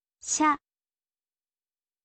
ออกเสียง: sha, ชะ
พยัญชนะนี้ไม่มีในภาษาไทยค่ะ มันใกล้กับ “ชะ” แต่เป็น “sha” ในภาษาอังกฤษ “sha” และ “cha” เป็นเสียงแตกต่างกัน ตั้งใจฟังเสียงและเลียนแบบกันเถอะ